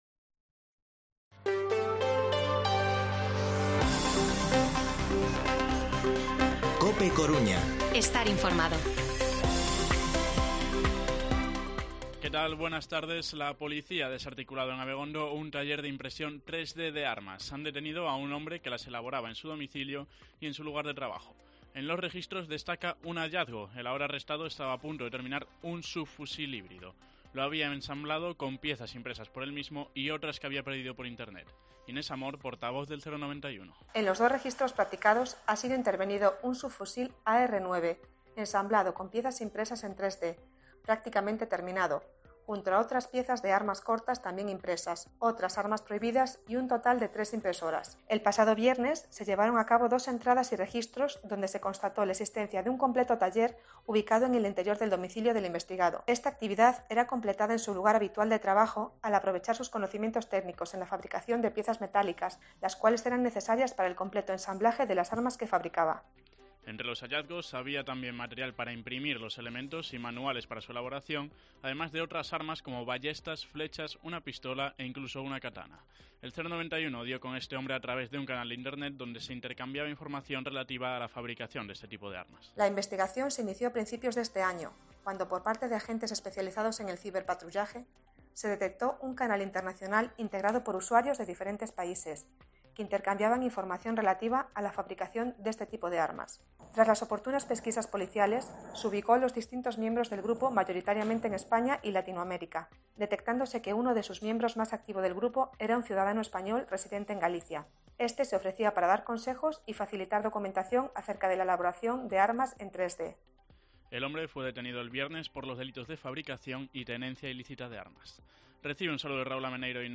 Informativo Mediodía COPE A Coruña jueves, 11 de agosto de 2022 14:20-14:30